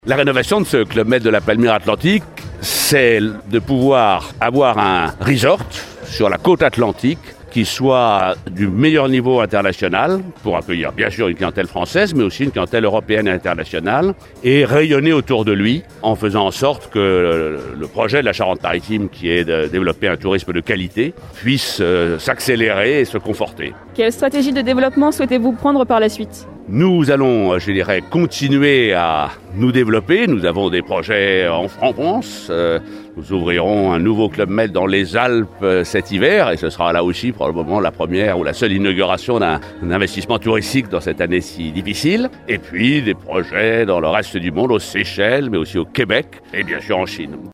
Lors de l’inauguration hier.
On écoute Henri Giscard d’Estaing :